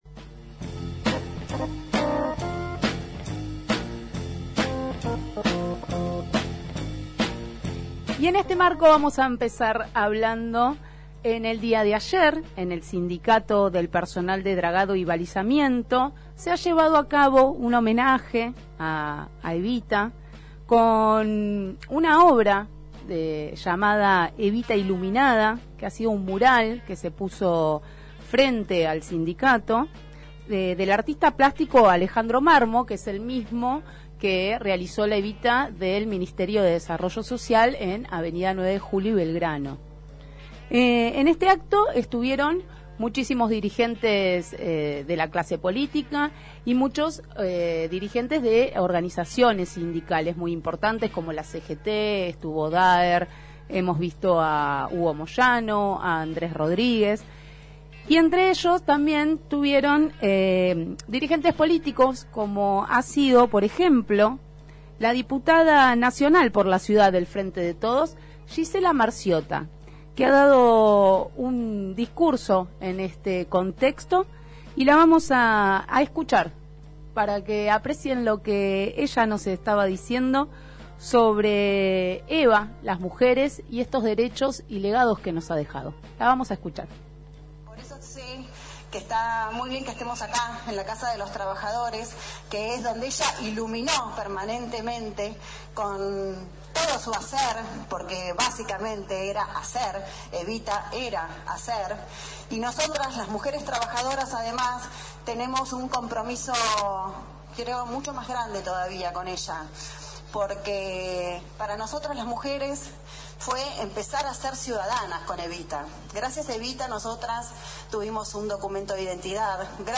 El acto convocó a más de 200 militantes del ámbito sindical, social y político.